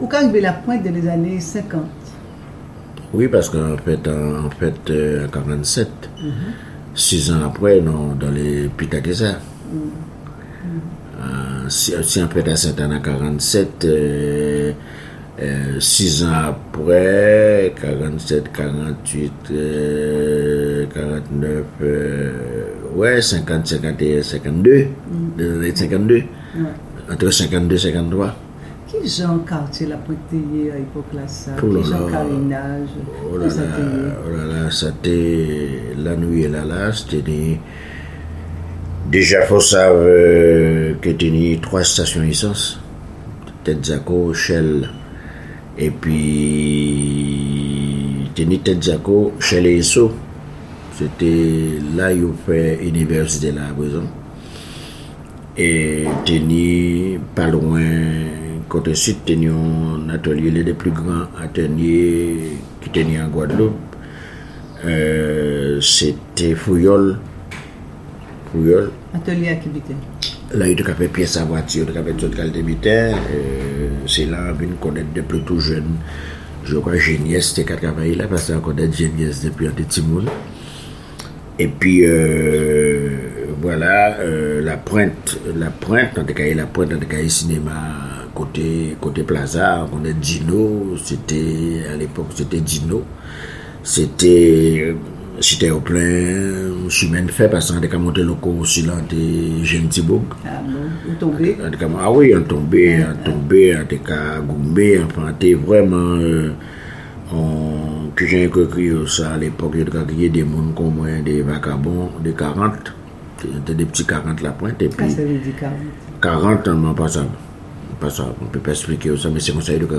Intégralité de l'interview.